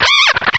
cry_not_simisage.aif